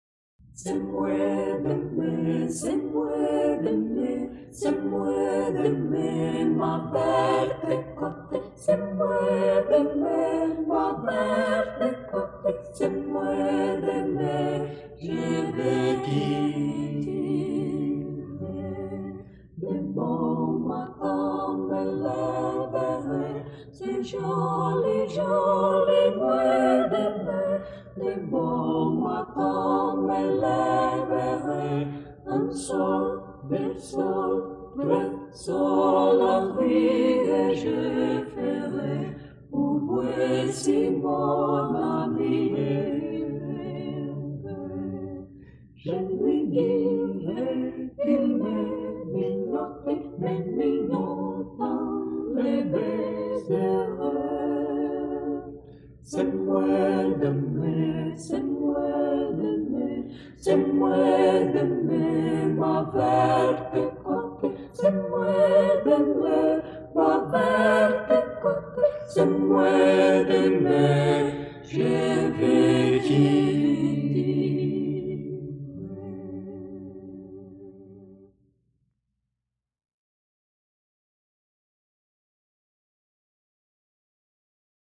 Sopranos
Contraltos
Tenores
Baixos
Renascença Francesa